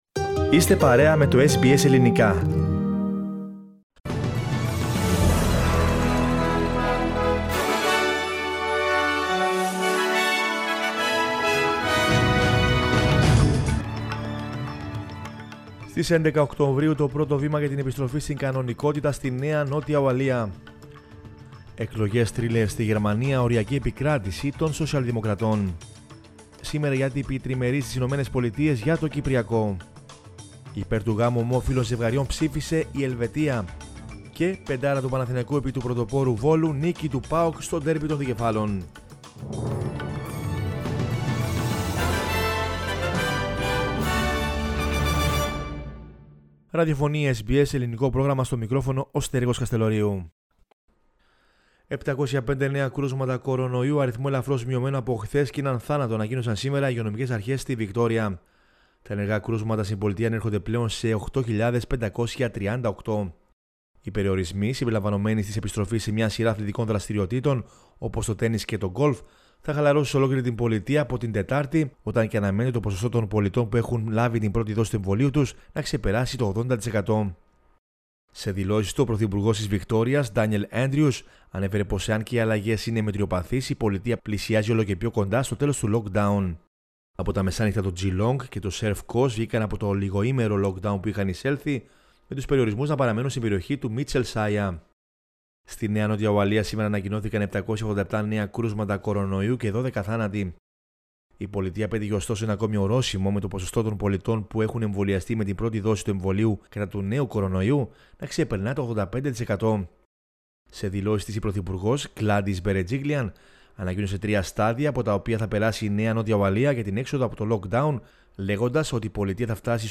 News in Greek from Australia, Greece, Cyprus and the world is the news bulletin of Monday 27 September 2021.